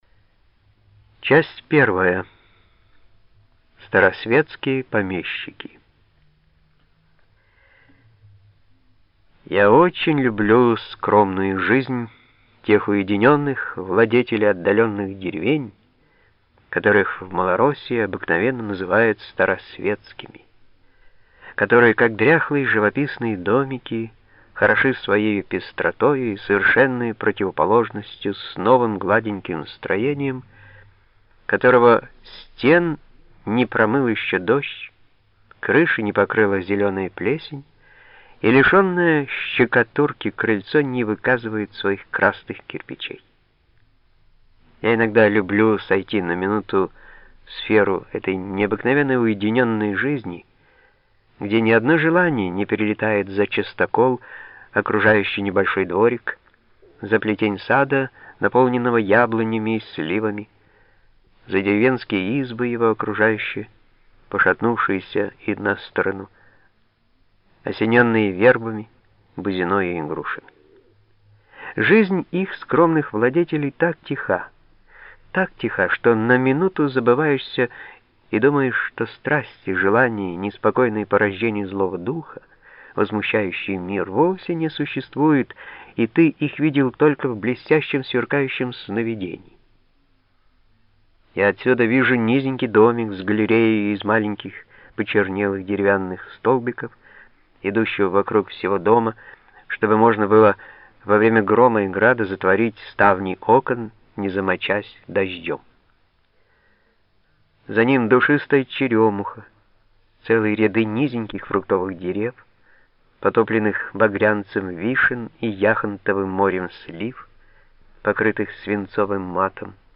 Аудиокнига Старосветские помещики
Качество озвучивания весьма высокое.